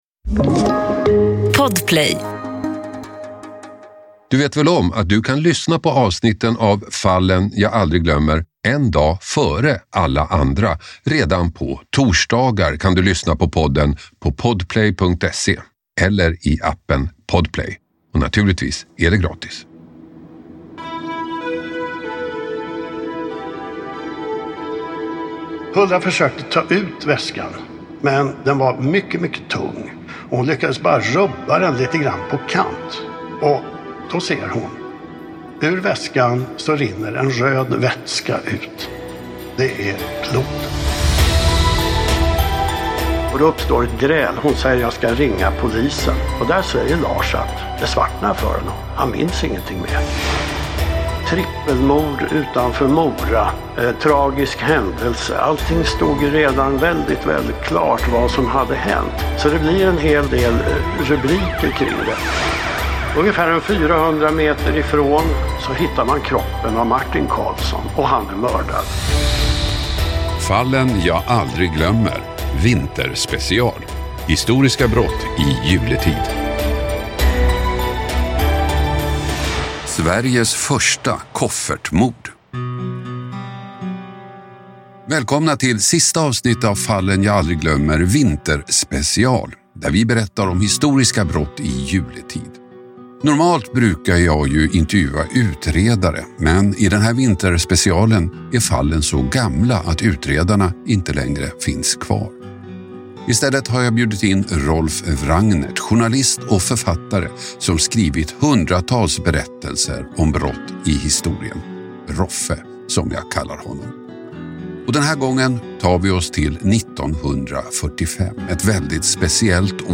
Hasse Aro får sällskap i studion